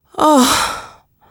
al_junc_vomit14.wav